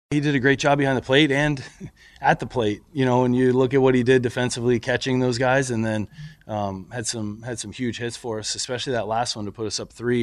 Manager Donnie Kelly admired the work of catcher Joey Bart, at the plate and behind the plate.